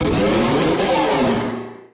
Cri de Méga-Blizzaroi dans Pokémon HOME.
Cri_0460_Méga_HOME.ogg